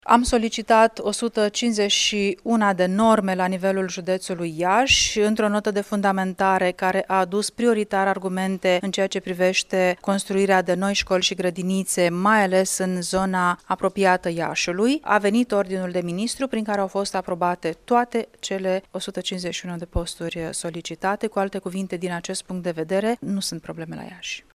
Peste 95 la sută din posturile didactice sunt acoperite, după ce, în vară, s-a solicitat o creștere a numărului de norme cu 151, a precizat șefa Inspectoratului Școlar Județean, Luciana Antoci.